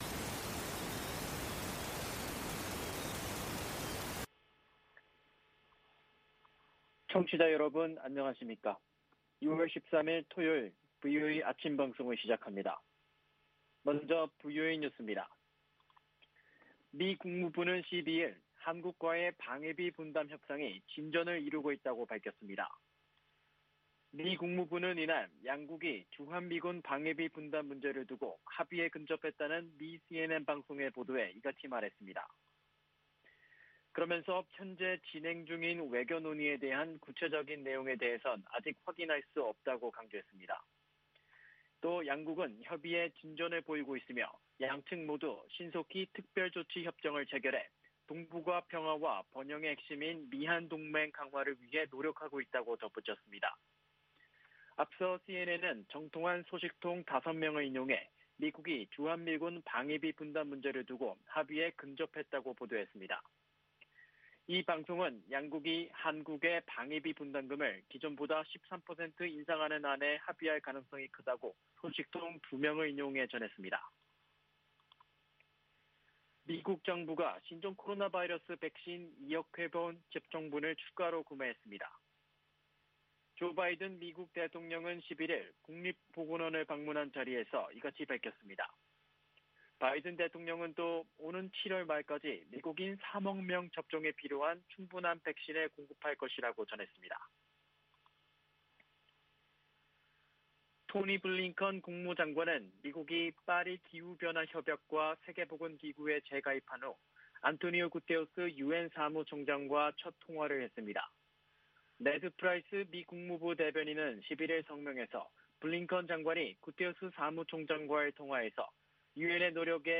세계 뉴스와 함께 미국의 모든 것을 소개하는 '생방송 여기는 워싱턴입니다', 아침 방송입니다.